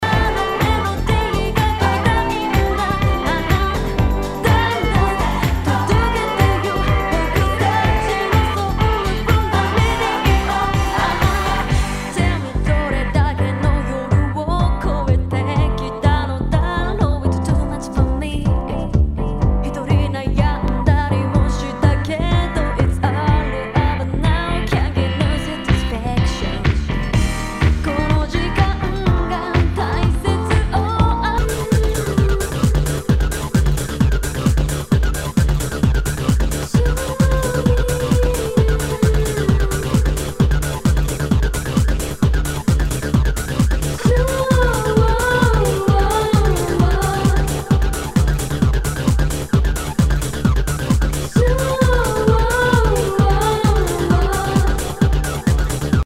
HOUSE/TECHNO/ELECTRO
ナイス！ハウス・ミックス！